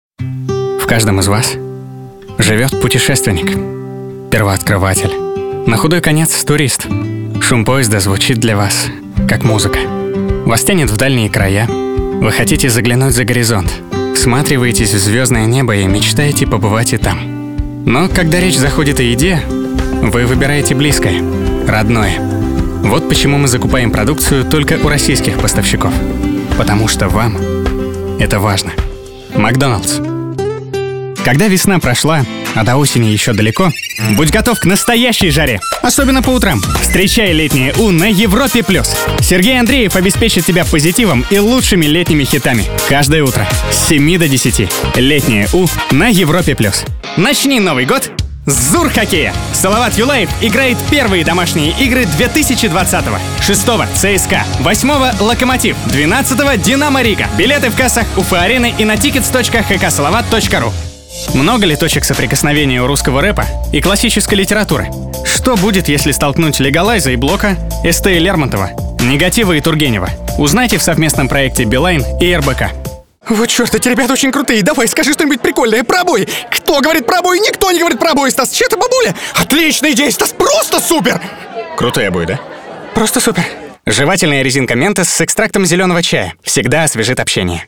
Основное демо
Муж, Рекламный ролик
SE X1, Long VoiceMaster, Scarlett 2i2